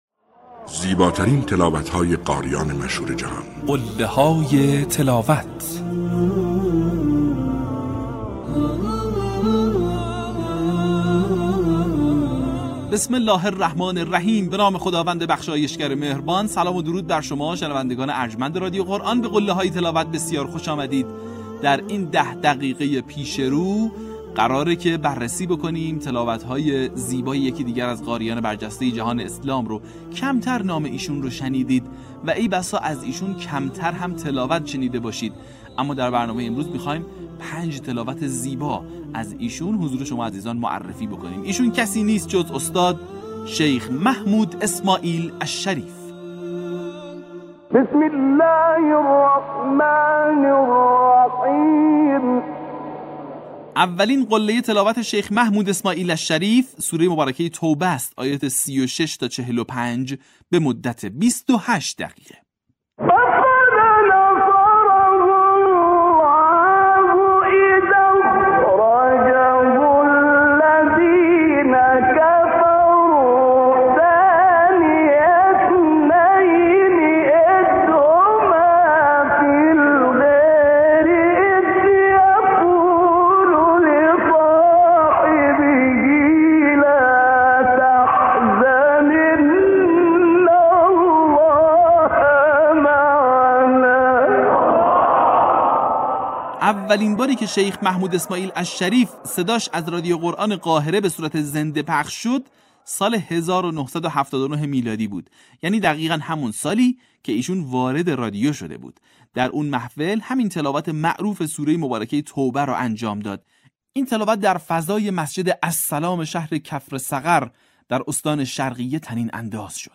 به همین منظور برترین و برجسته‌ترین مقاطع از تلاوت‌های شاهکار قاریان به نام جهان اسلام که مناسب برای تقلید قاریان است با عنوان «قله‌های تلاوت» ارائه و بازنشر می‌شود.